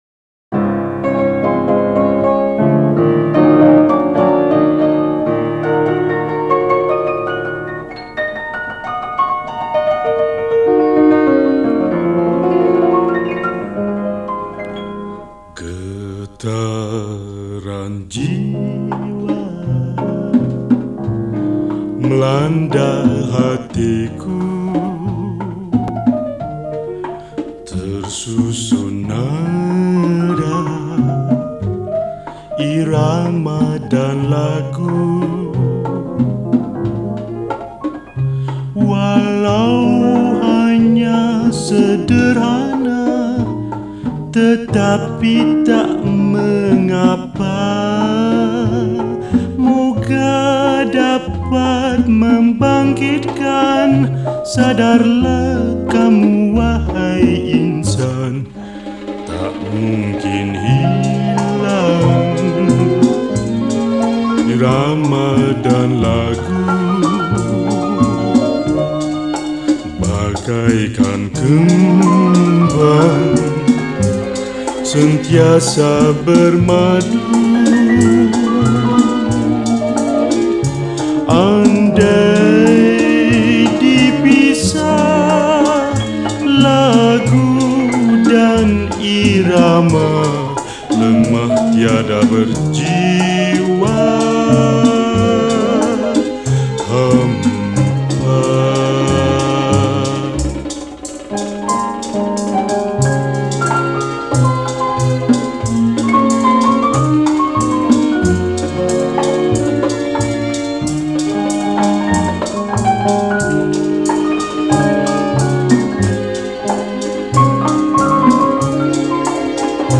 Malay Songs , Recorder in 2 parts